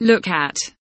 look at kelimesinin anlamı, resimli anlatımı ve sesli okunuşu